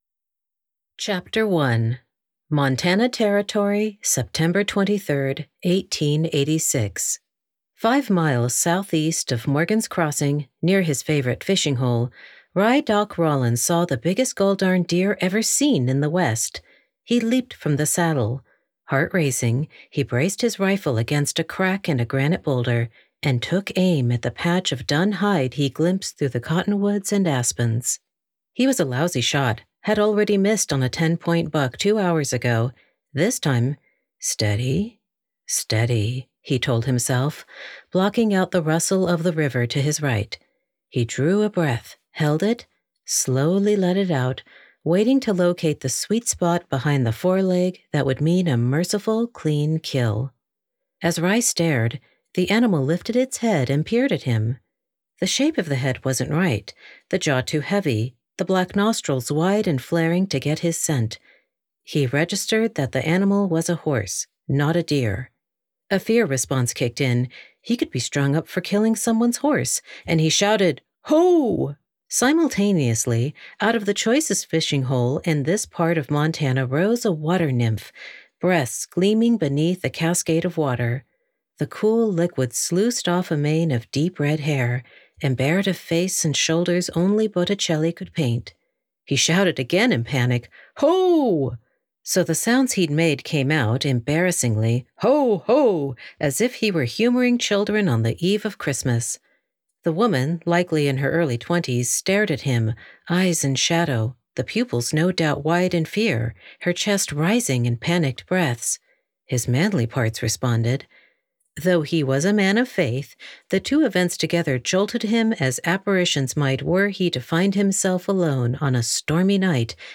New audiobook coming to Audible